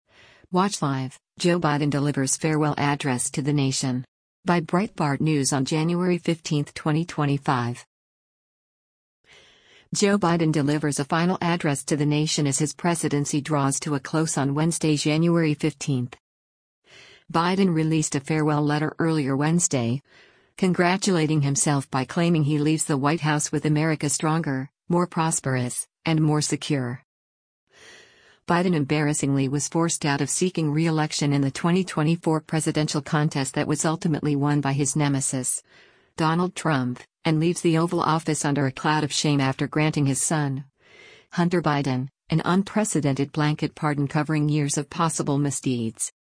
Watch Live: Joe Biden Delivers Farewell Address to the Nation
Joe Biden delivers a final address to the nation as his presidency draws to a close on Wednesday, January 15.